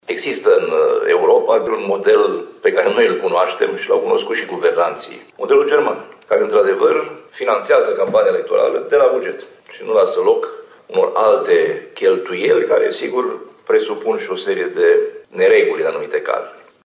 Finanţarea campaniei electorale de la bugetul de stat trebuia făcută cu mult timp înainte, a declarat, săptămâna trecută la Reşiţa, vicepreşedintele Partidului Naţional Liberal, Sorin Frunzăverde.